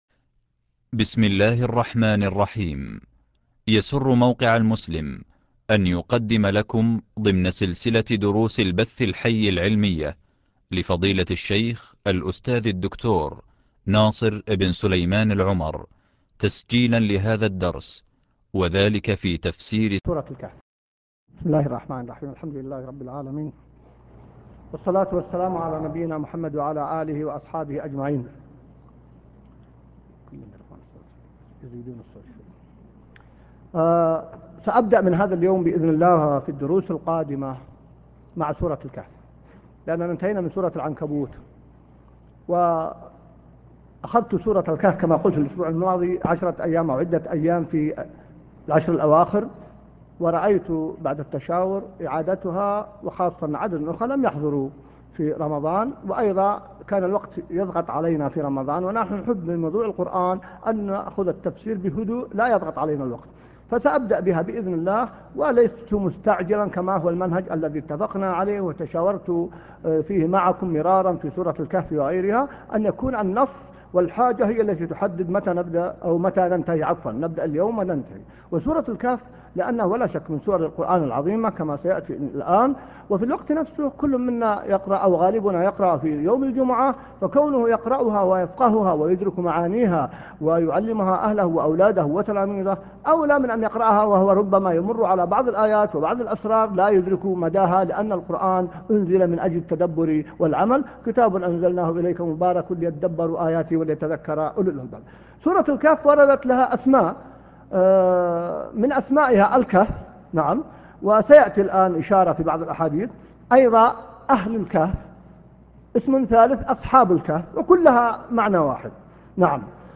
الدرس (1) من تفسير سورة الكهف - المقدمة | موقع المسلم